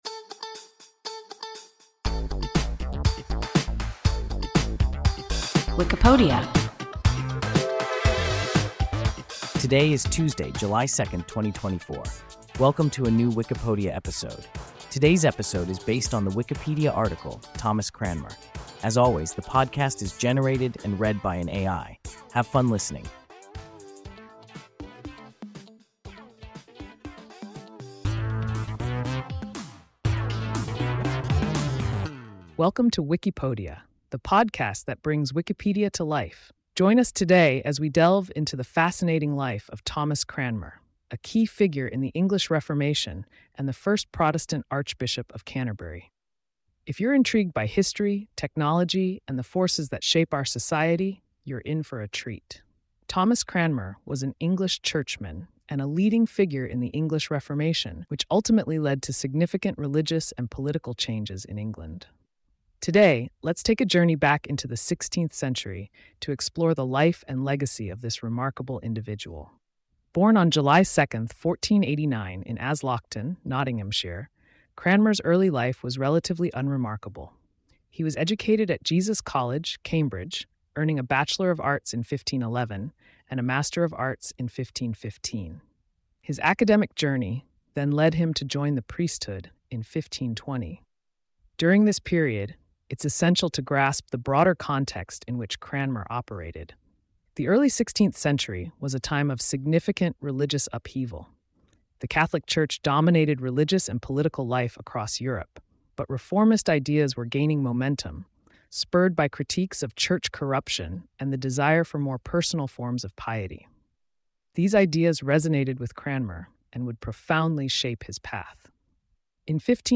Thomas Cranmer – WIKIPODIA – ein KI Podcast